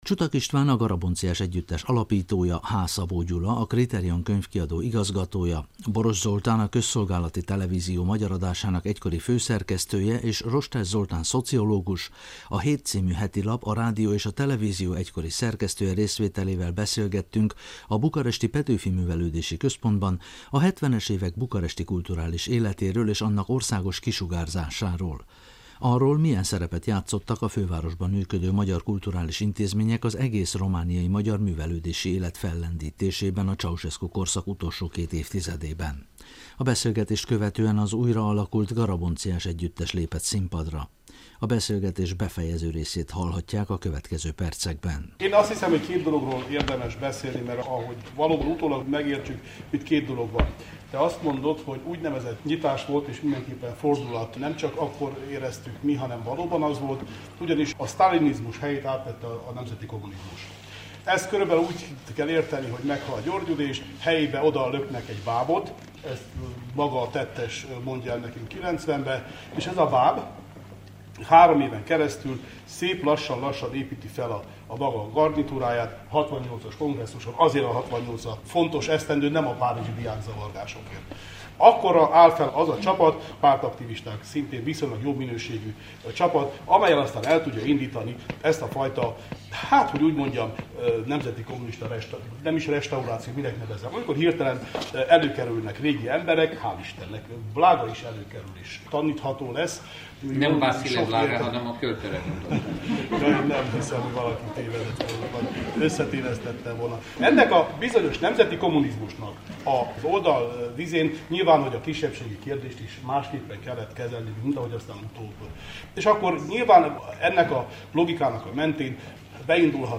A beszélgetés befejező részét hallhatják a következő percekben.